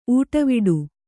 ♪ ūṭaviḍu